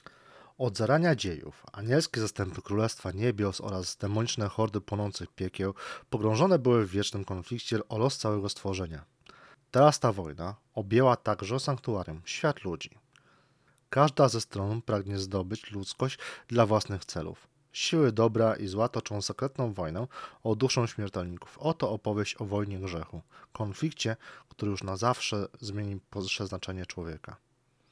Oba nagrania są bez obróbki, dzięki czemu sami będziecie mogli ocenić jakość recenzowanego modelu.
Próbka audio bez obróbki – Shure SM7B